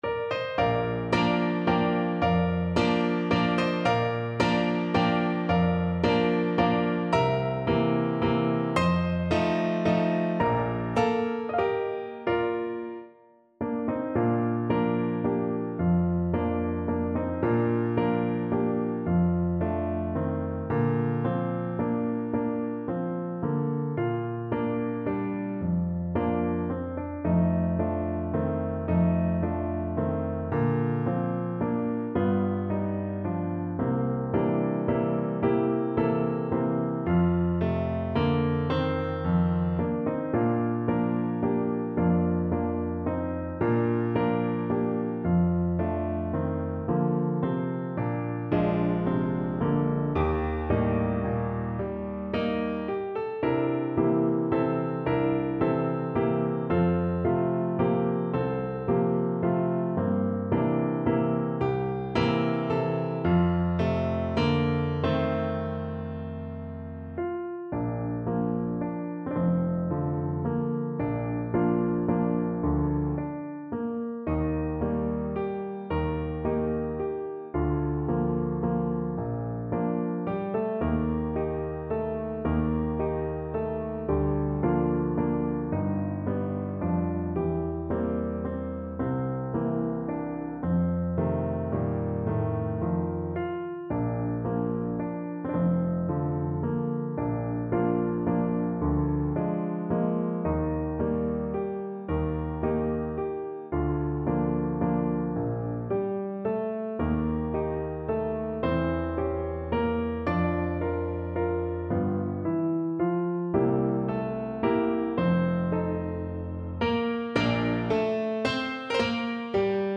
Play (or use space bar on your keyboard) Pause Music Playalong - Piano Accompaniment Playalong Band Accompaniment not yet available transpose reset tempo print settings full screen
3/4 (View more 3/4 Music)
Valse moderato espressivo = 110
Bb major (Sounding Pitch) (View more Bb major Music for Voice )
Pop (View more Pop Voice Music)